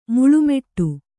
♪ muḷu meṭṭu